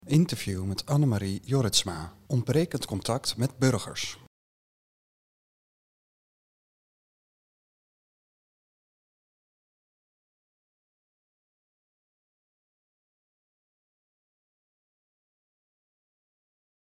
Interview met Annemarie Jorritsma